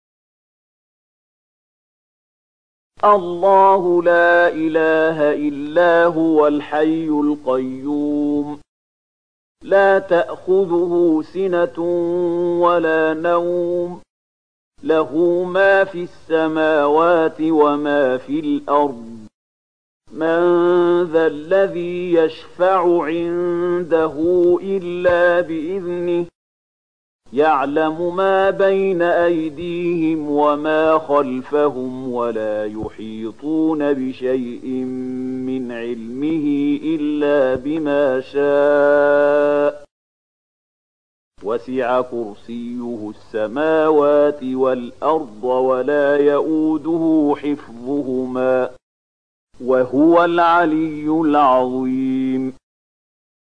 002255 Surat Al-Baqarah ayat 255 dengan bacaan murattal Syaikh Mahmud Khalilil Hushariy: